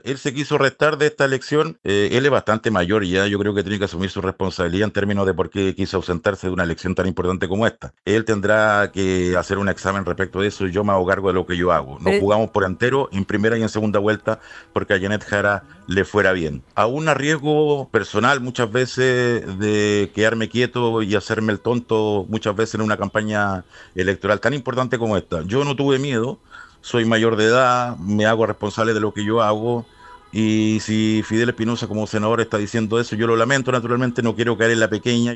Consultado el diputado reelecto Héctor Ulloa, uno de los voceros de la campaña de Jara y aludido por el socialista, sentenció que él no se va a quedar en la “pelea chica” y replicó que Espinoza (que asegura que fue desplazado de la campaña) debe dar también explicaciones por su ausencia.